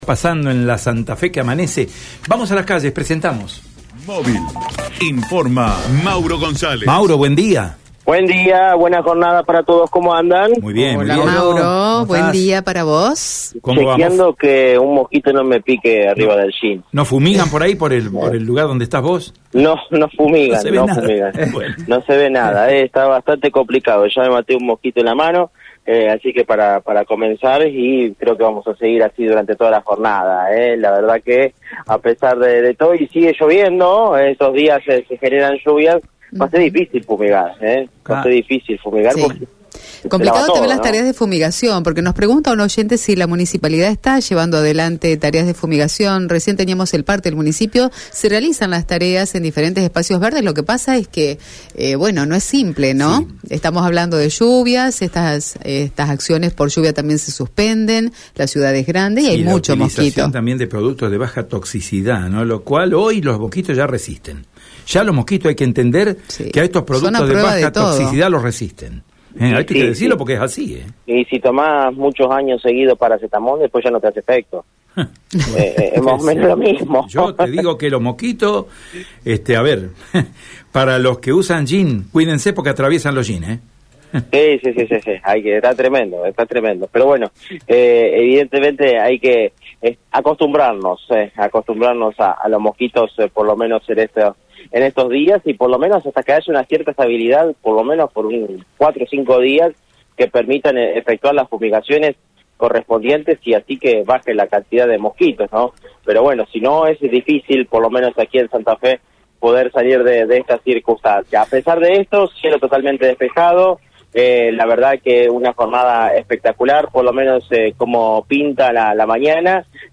«Desde las primeras horas de la jornada, los santafesinos realizan filas a la espera de cobrar el bono», señaló el móvil de Radio EME.